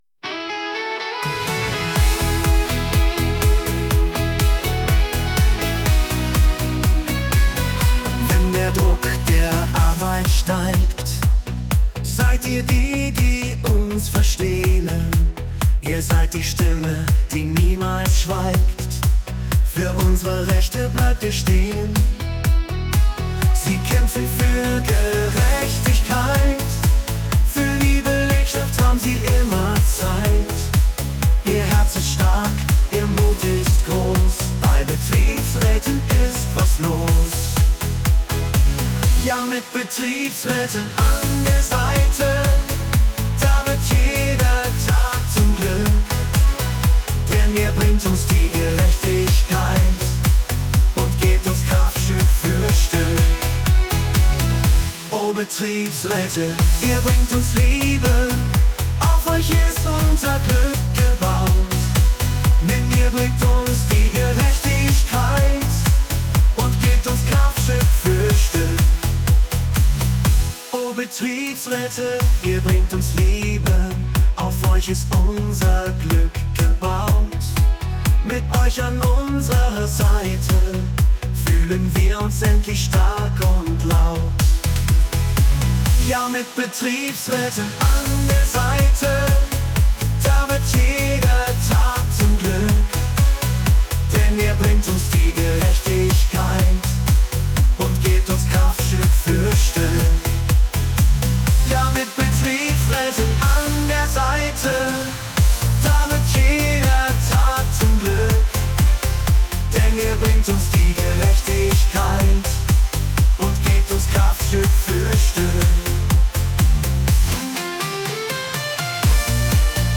Betriebsrats-Songs (KI-generiert)
Schlager-Version
betriebsratssong---schlager.mp3